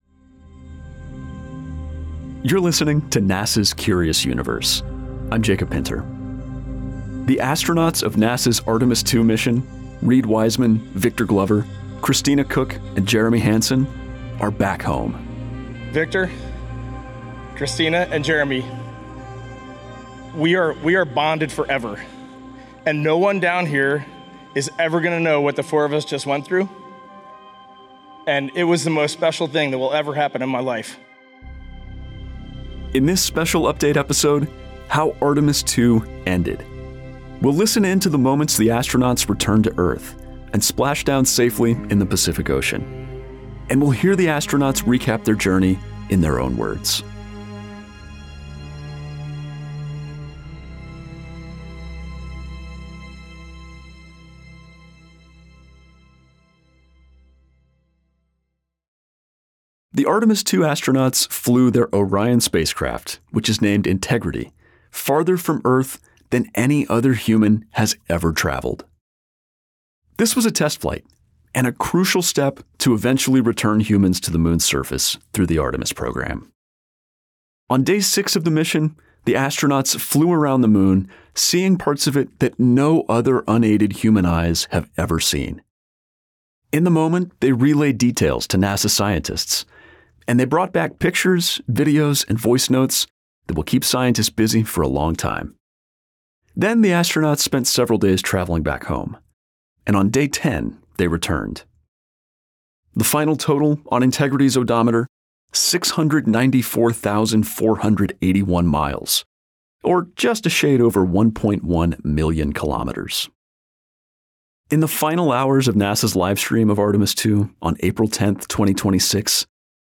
NASA’s Artemis II astronauts are back home. Hear reactions from the Artemis II crew—Reid Wiseman, Victor Glover, Christina Koch, and Jeremy Hansen—as they returned to Earth.